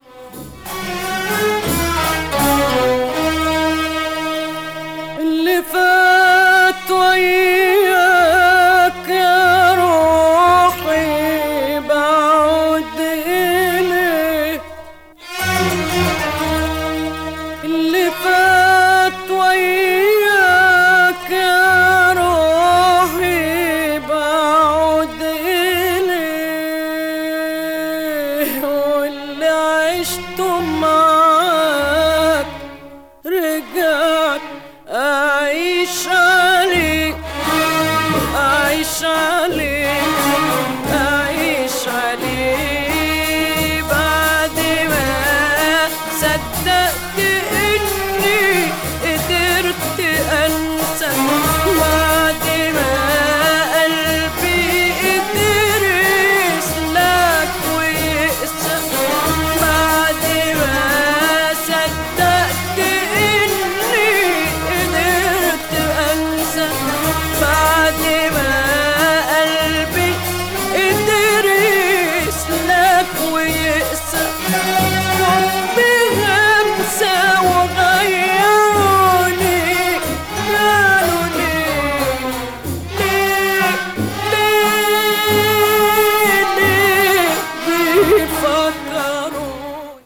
media : EX-/EX-(薄いスリキズによるわずかなチリノイズが入る箇所あり)
arab   egypt   oritental   traditonal   world music